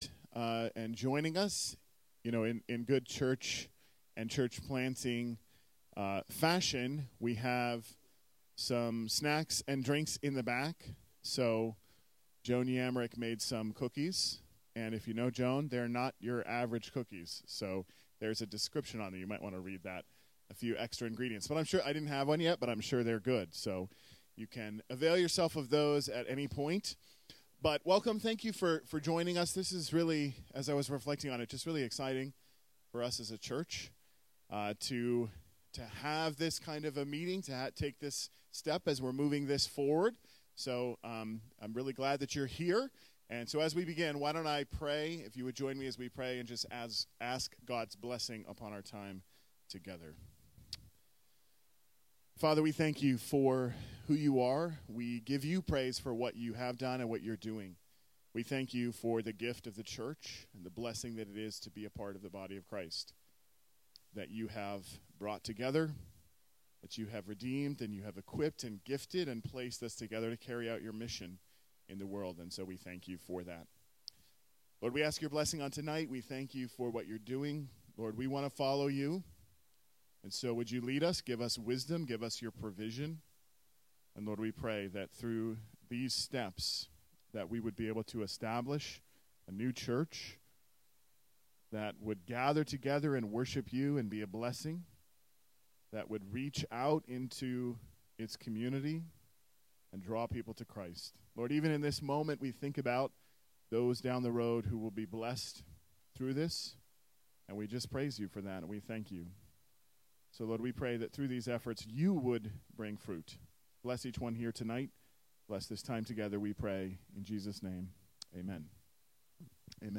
On Monday, June 9, we held an informational session about the vision and plans for our upcoming church plant. It was a meaningful time of conversation, information, and Q&A as we explored how God is leading us to multiply his work in a new community.